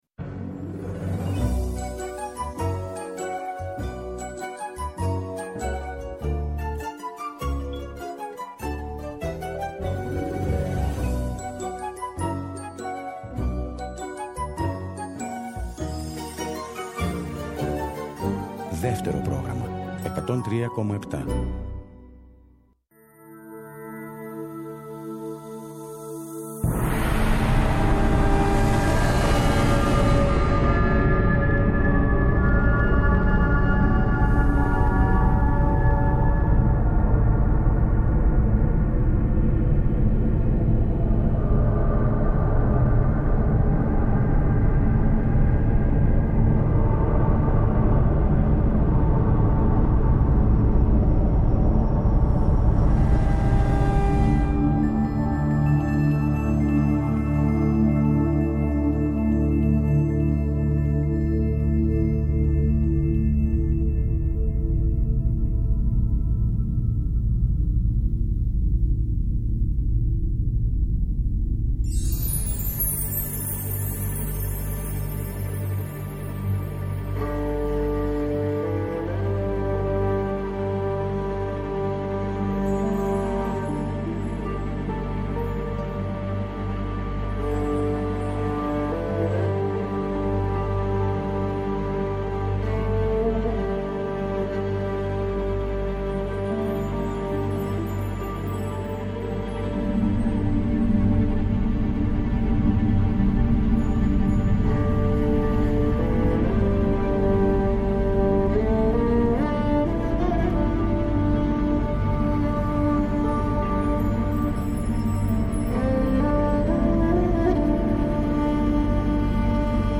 κάθε Σάββατο και Κυριακή στις 19.00 έρχεται στο Δεύτερο Πρόγραμμα με ένα ραδιοφωνικό – μουσικό road trip